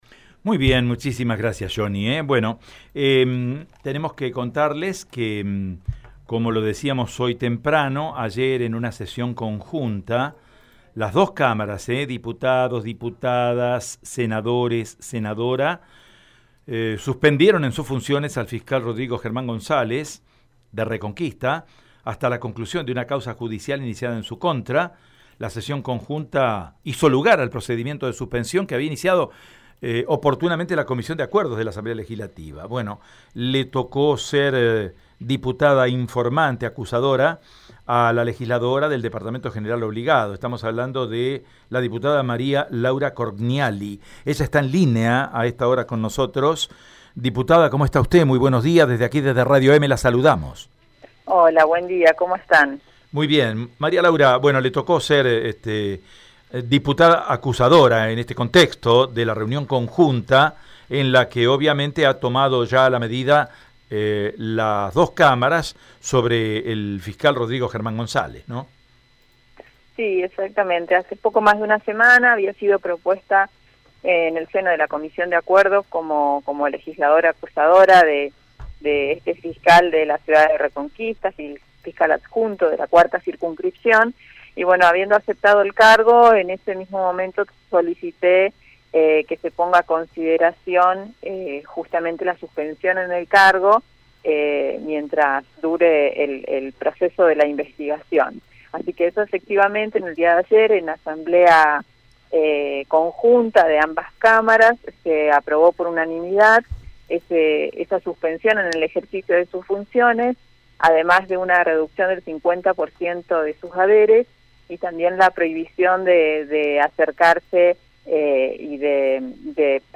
En diálogo con Radio EME, la diputada María Laura Corgniali indicó que “hace poco más de una semana había sido propuesta en la comisión de acuerdo como legisladora acusadora del fiscal adjunto y habiendo aceptado el cargo, solicité que se considere la suspensión en el cargo mientras dure la investigación”.